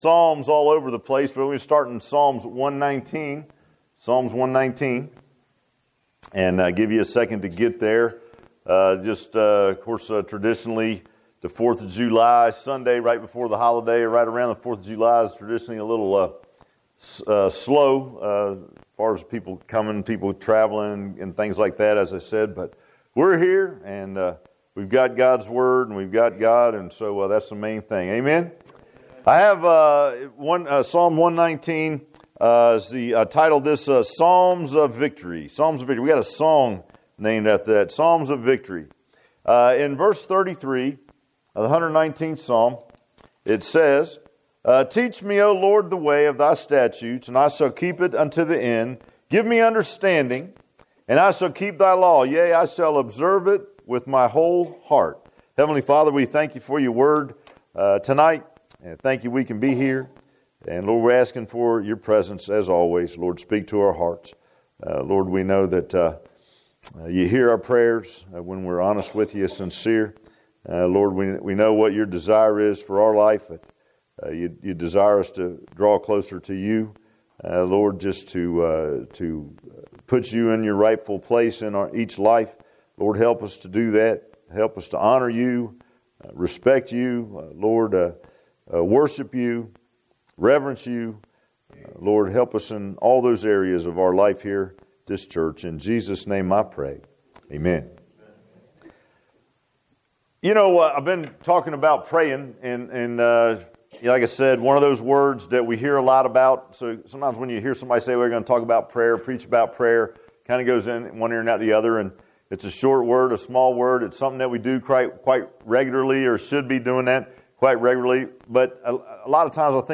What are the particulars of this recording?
Psalm 119:33-34 Service Type: Sunday PM Bible Text